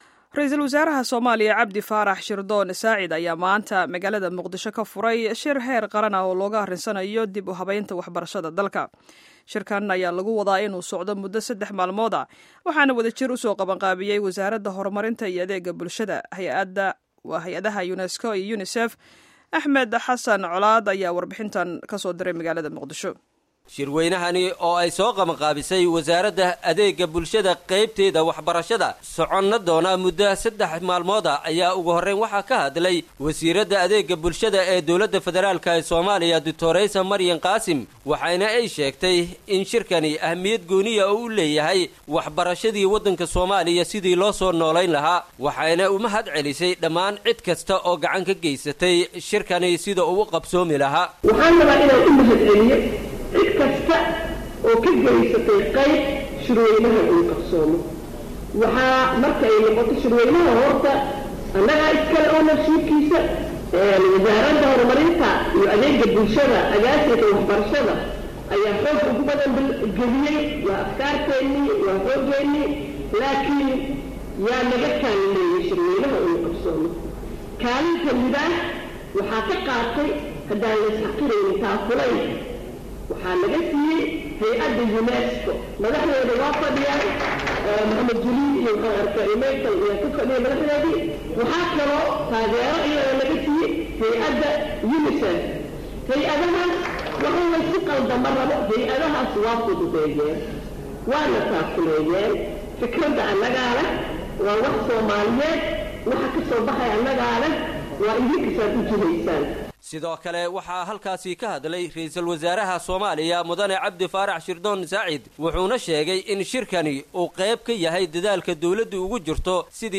Warbixinta Shirka Dib-u-habeynta Waxbarashada